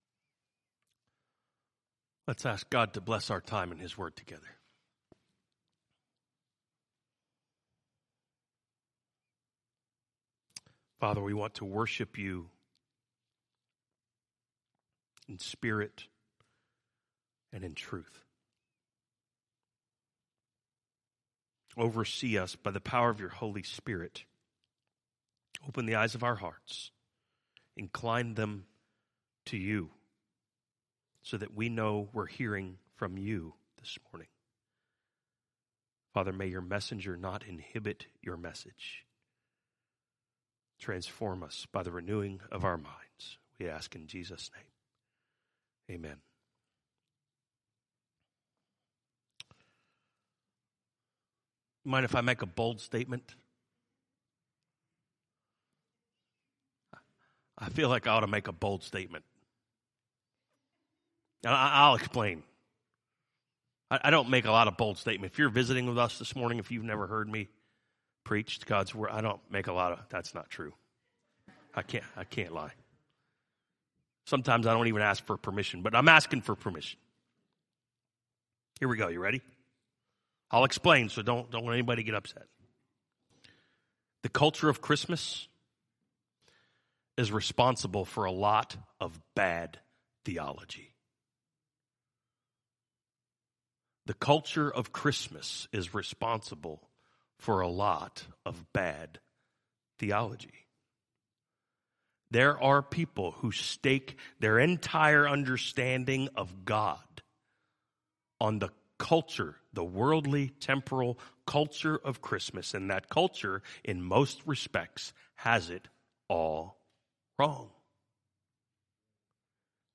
From Series: "2022 Sermons"